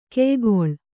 ♪ keygoḷ